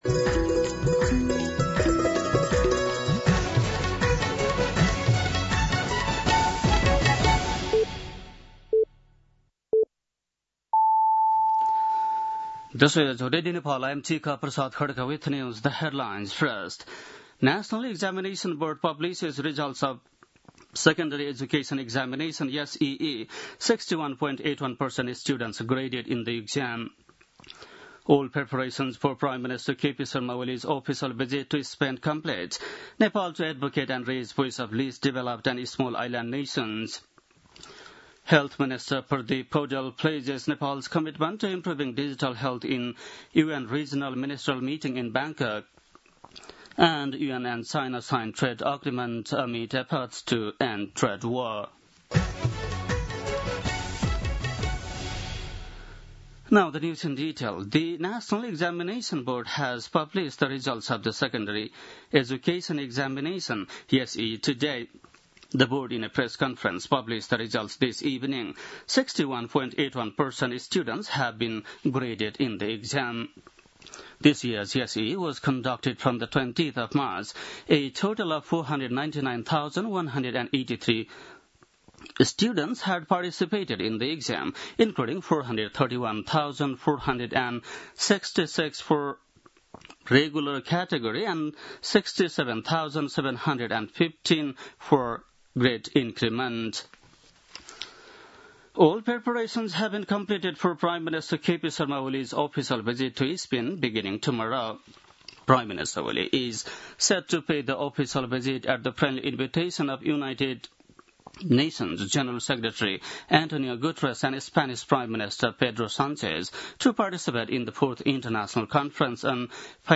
बेलुकी ८ बजेको अङ्ग्रेजी समाचार : १३ असार , २०८२